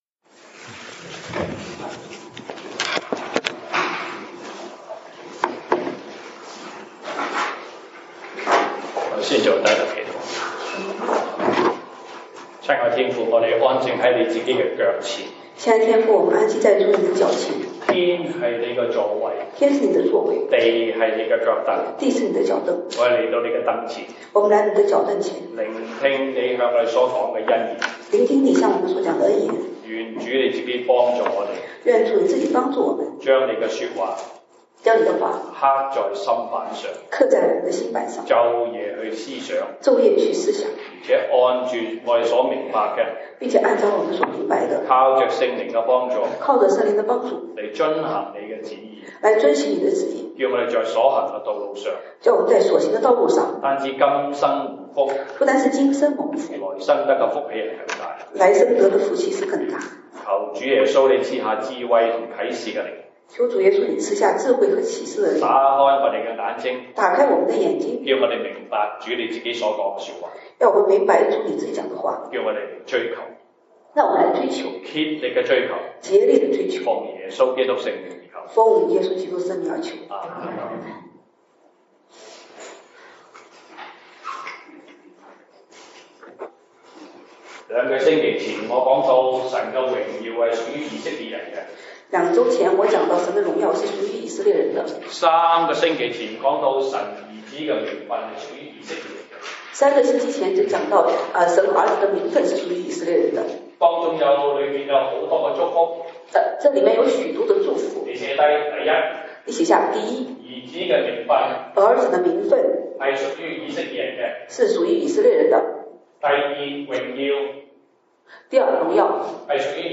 西堂證道(粵語/國語) Sunday Service Chinese: 有寶貝在其中